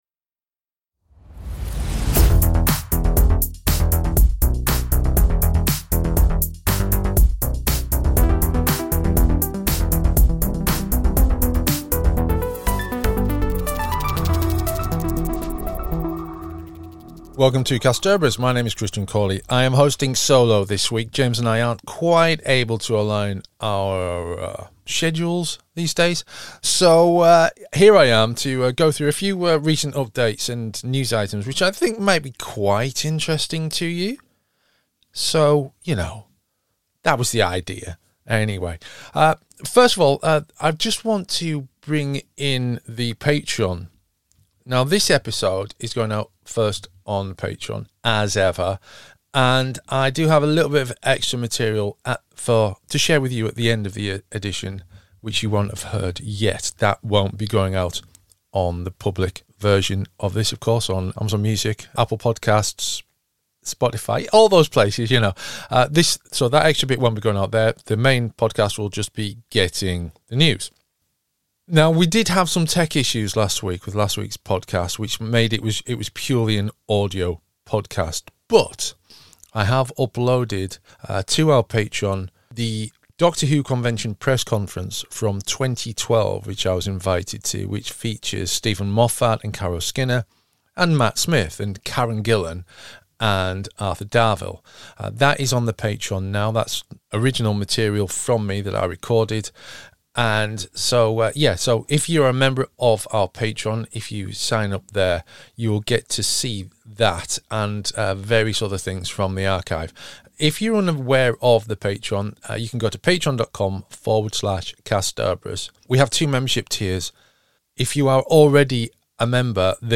This week's show is a news round up, looking at the key scifi/cult TV and movie stories of the past few weeks.